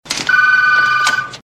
• digital phone ring.wav